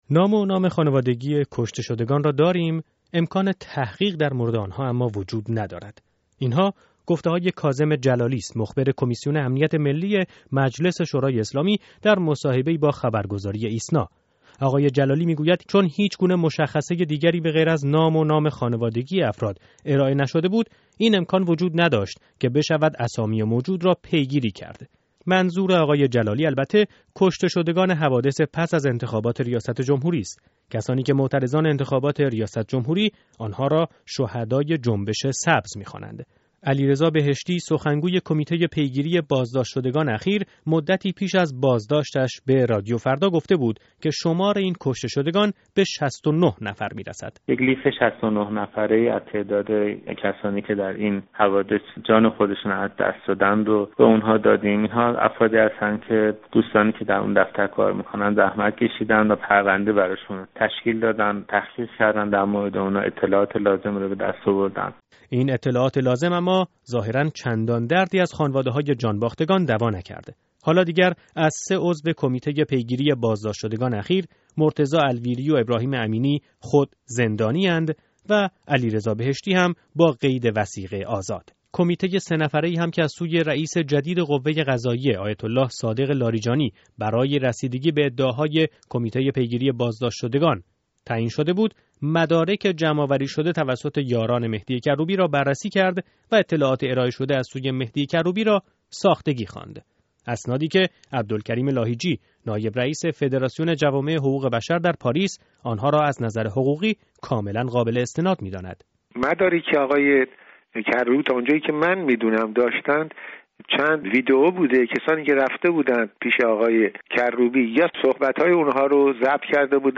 گزارش رادیویی در همین ارتباط